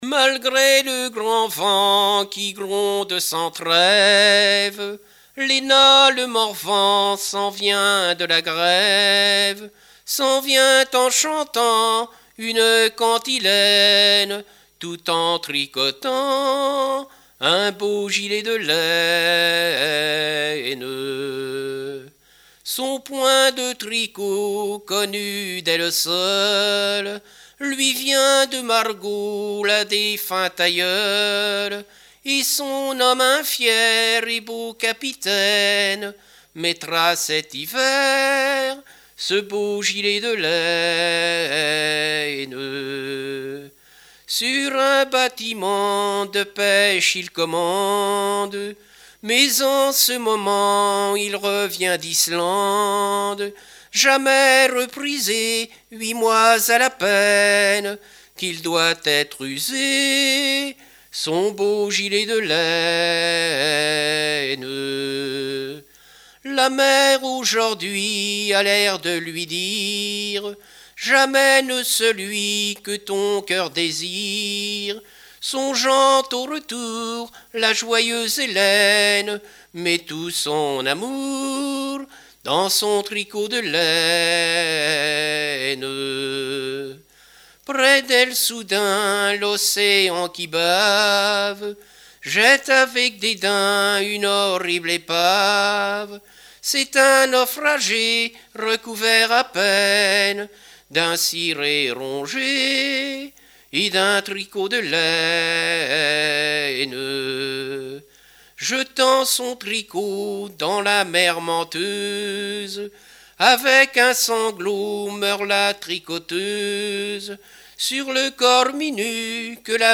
Genre strophique
chansons de variété et music-hall
Pièce musicale inédite